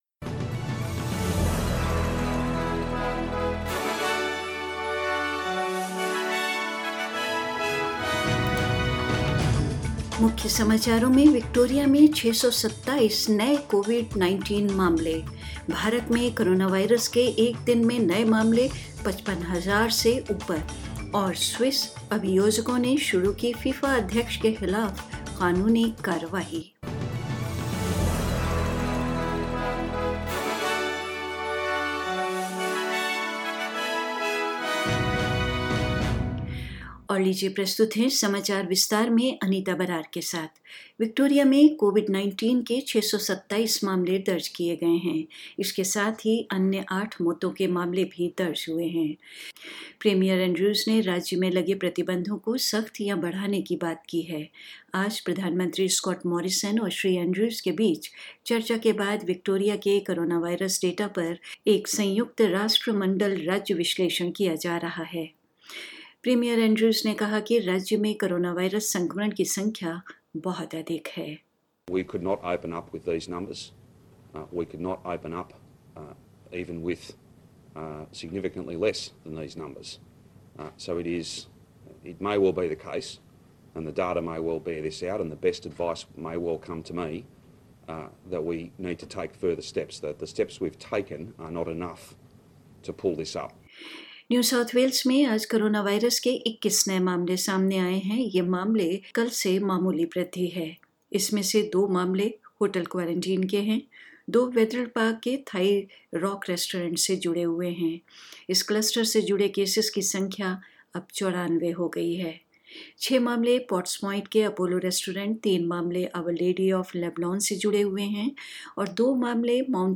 News in Hindi 31st July 2020